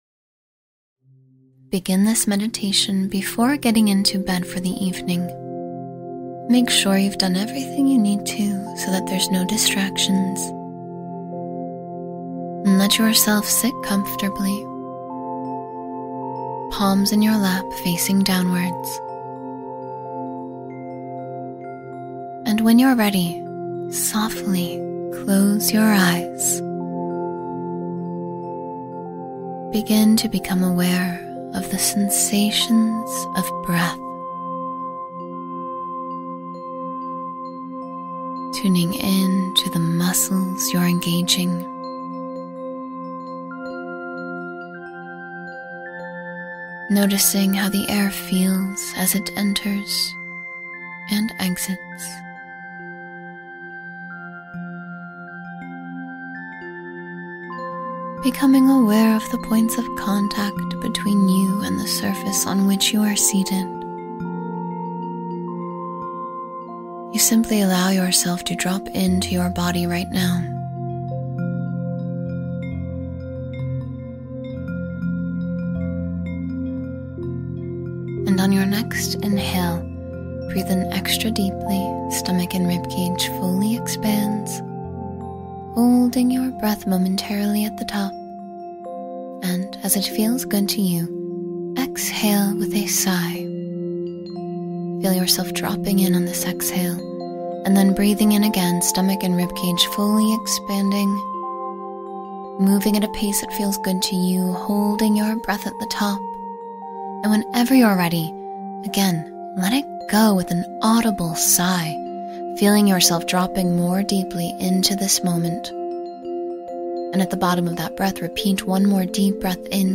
Calm Before Sleep (Female Voice)